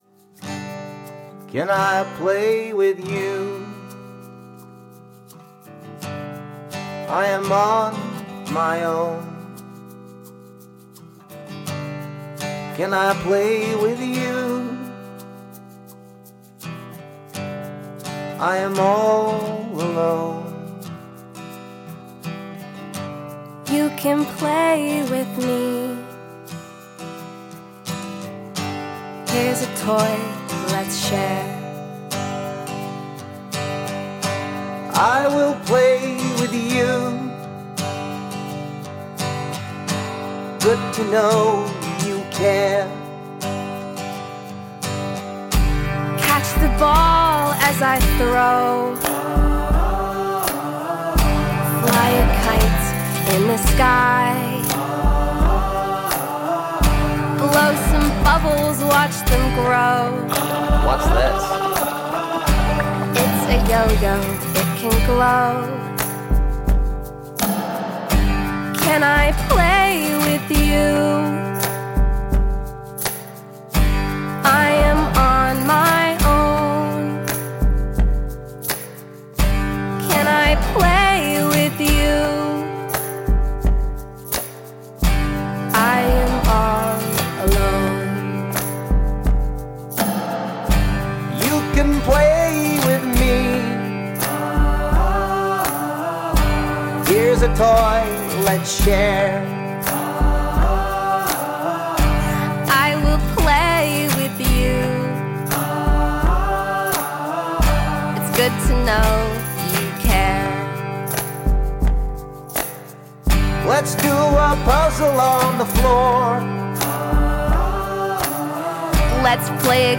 Robot songs